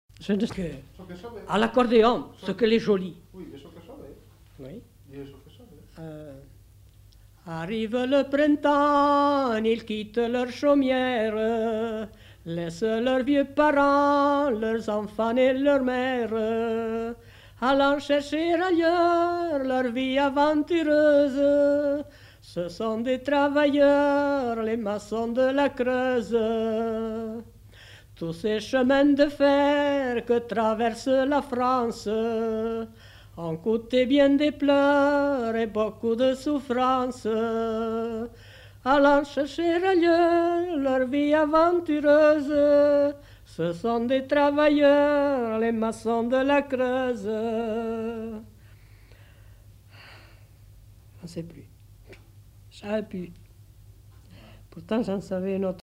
Aire culturelle : Périgord
Lieu : Saint-Amand-de-Vergt
Genre : chant
Effectif : 1
Type de voix : voix de femme
Production du son : chanté
Description de l'item : fragment ; 2 c. ; refr.